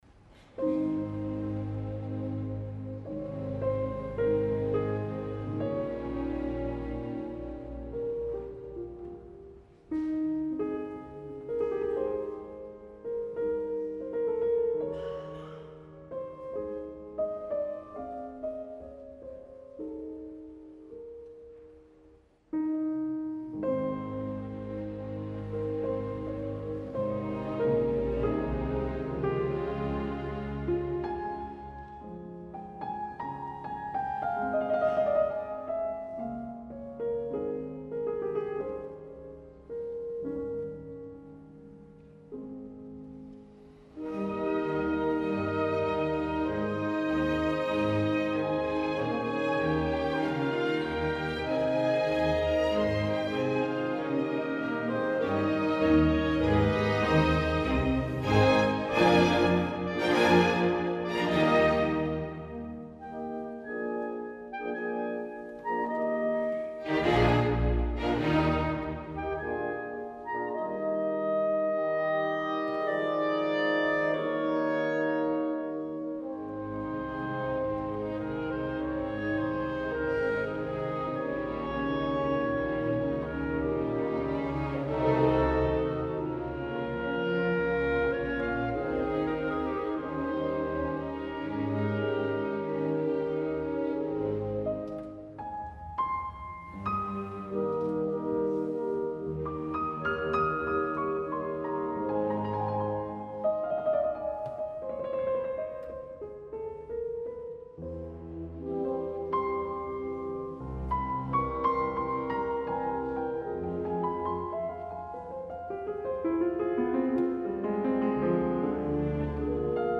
Com a contrapartida us proposo i em proposo escoltar el segon moviment per a piano i orquestra número 1 de Beethoven, quelcom que en a mi em sembla impossible que no acabi produint l’efecte terapèutic desitjat.
La versió és de la pianista Martha Argerich i l’ Orchestra della Svizzera italiana sota la direcció de Markus Poschner , en un concert que va tenir lloc el dia 12 de juny a Lugano